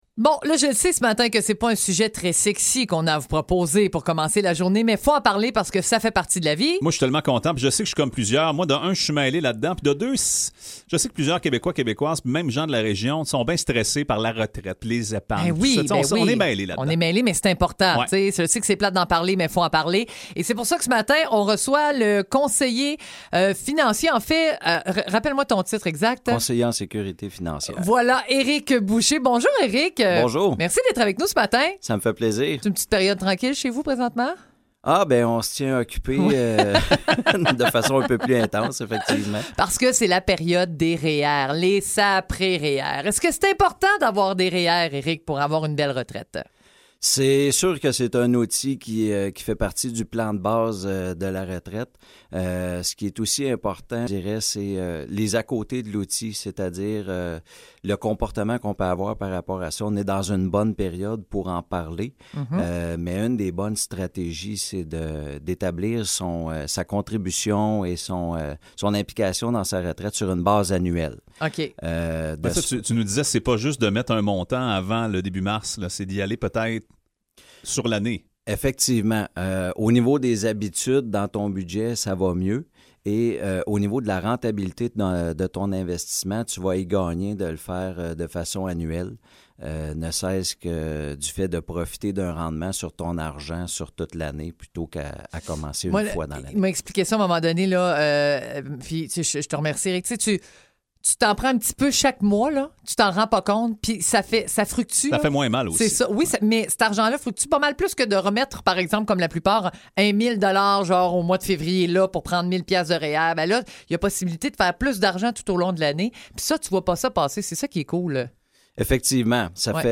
Entrevue
sur les ondes de WOW